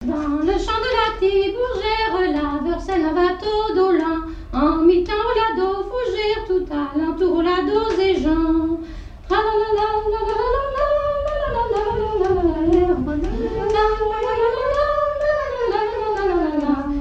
danse : branle : avant-deux
airs de danses et chansons traditionnelles
Pièce musicale inédite